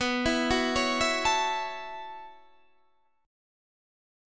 B7sus2sus4 chord